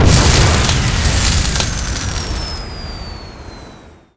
youexplode.wav